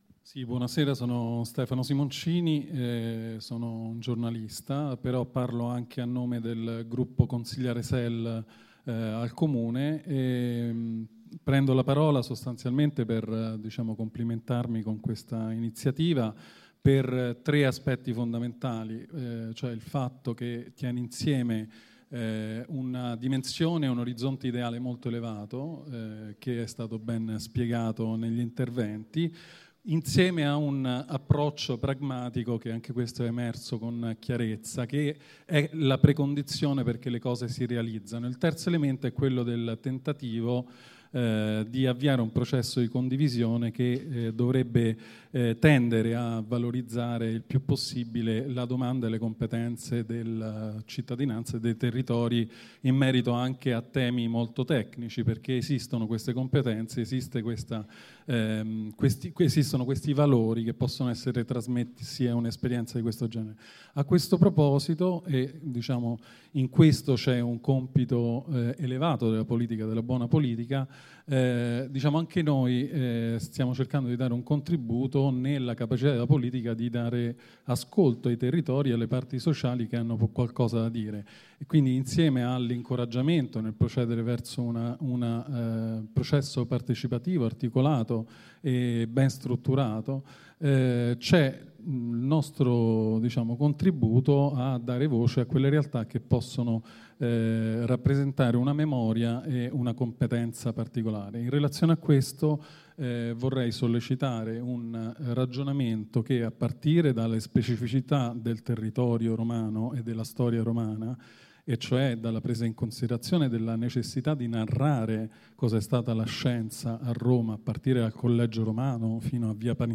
Registrazione integrale dell'incontro svoltosi il 29 maggio 2014 nella Sala MAXXI B.A.S.E. in via Guido Reni